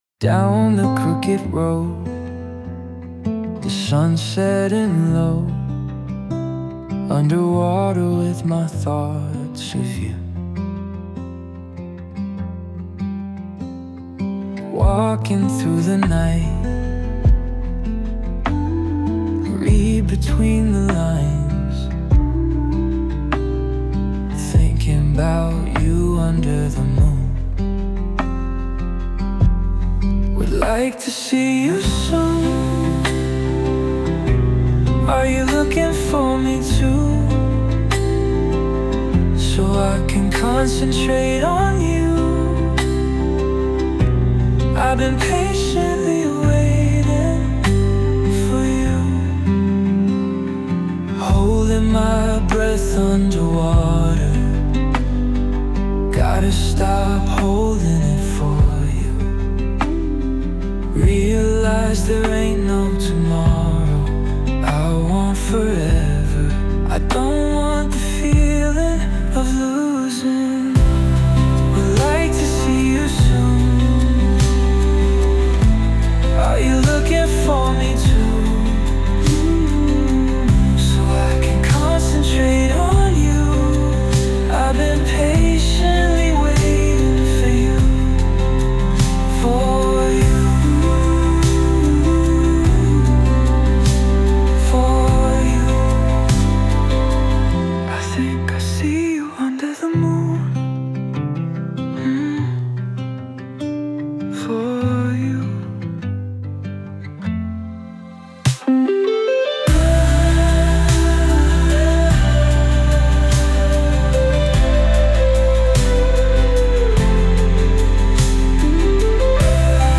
🎼 Generated Style Tags: Slow, Acoustic, Emotional, Relaxing, Soft
Both versions have identical lyrics and style, but they differ in vocal tone, delivery, or expression.